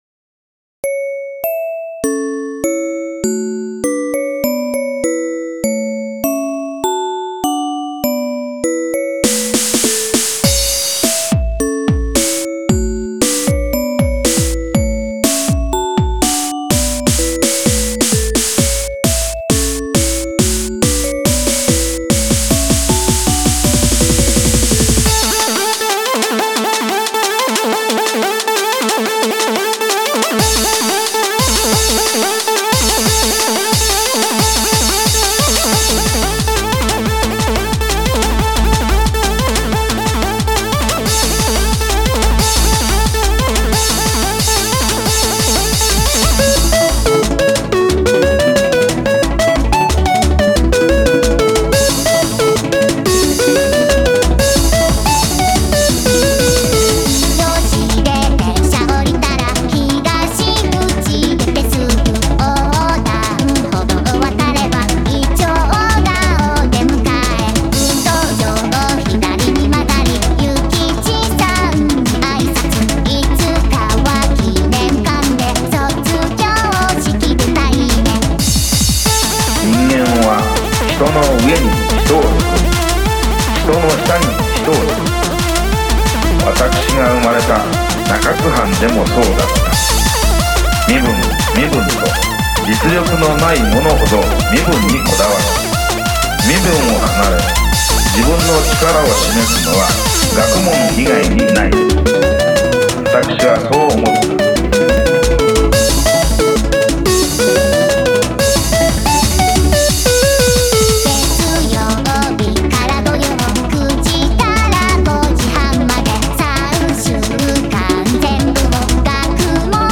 歌にはNEUTRINOというボカロ的なものを使っています。